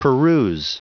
Prononciation du mot peruse en anglais (fichier audio)
Prononciation du mot : peruse